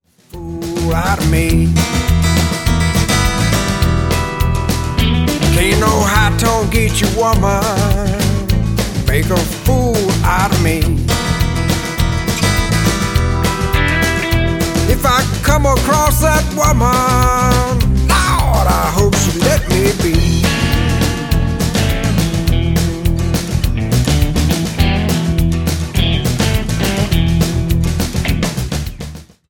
It’s sorta like a ZZ Top rhythm to Mojo lyrics.